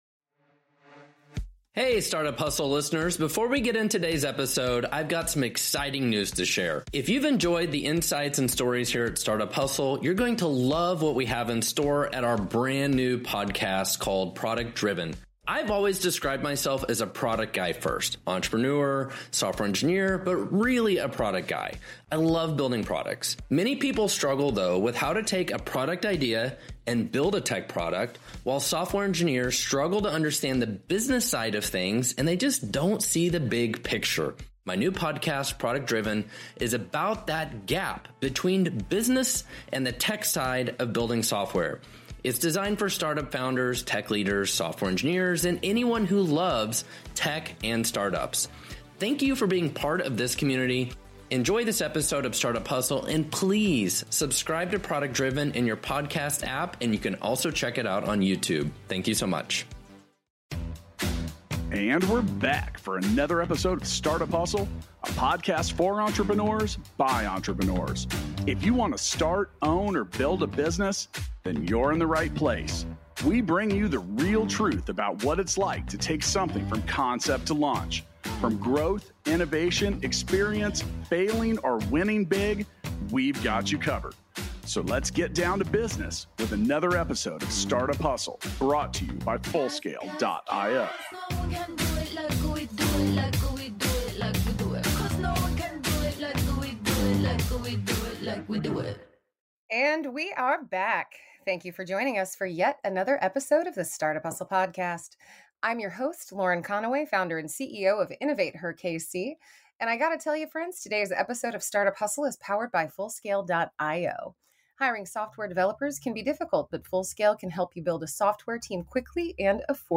for an insightful conversation on navigating your first year as an entrepreneur.